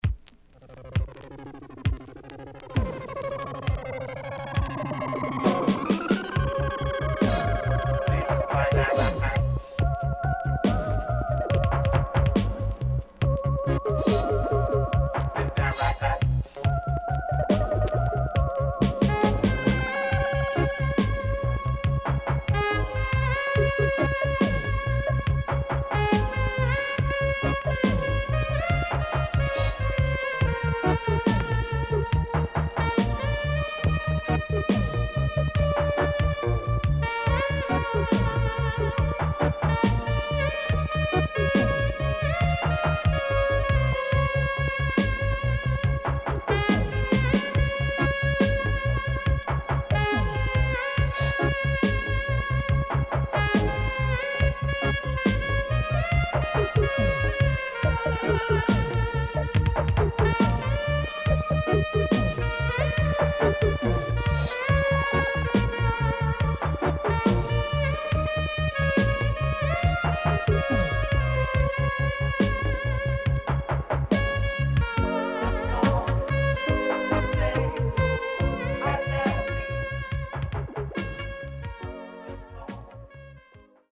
REGGAE / ROOTS
SIDE A:少しチリノイズ入ります。